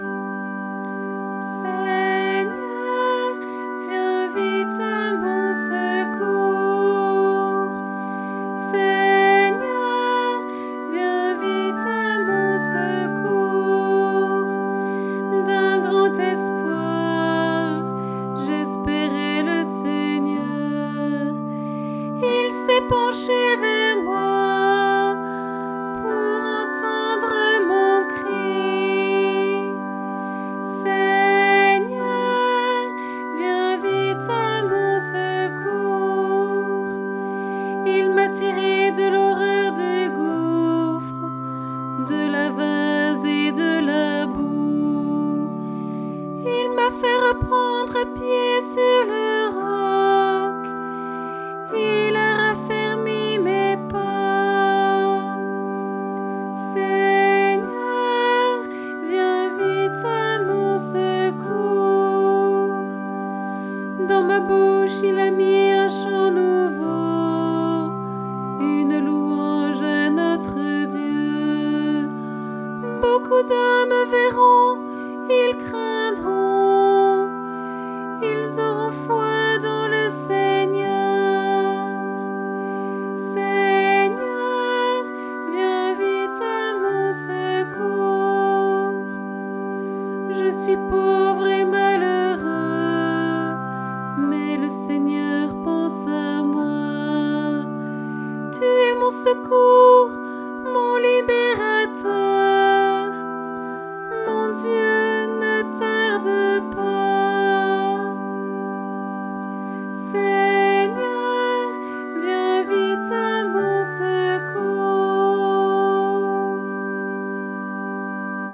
Antienne pour le 20e dimanche du Temps Ordinaire